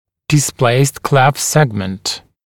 [dɪs’pleɪst kleft ‘segmənt][дис’плэйст клэфт ‘сэгмэнт]дистопированный сегмент расщелины